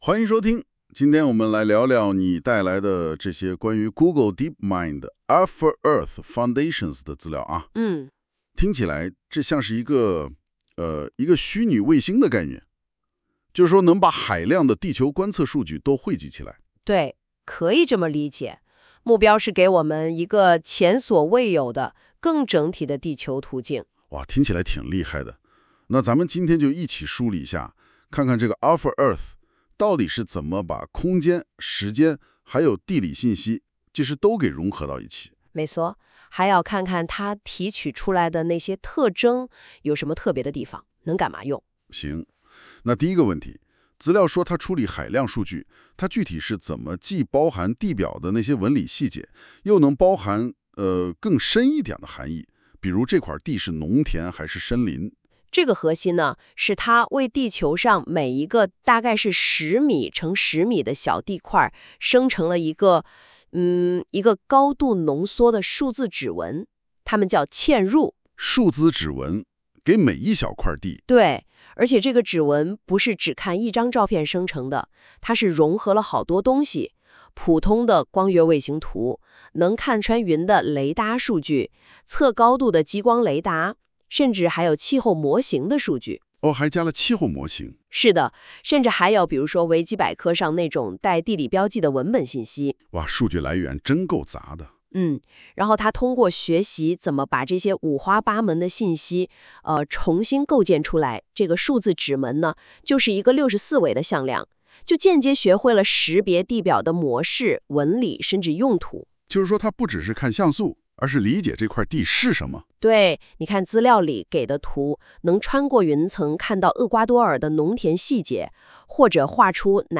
收听方式 在线收听 下载音频 音频内容由AI自动生成，仅供参考。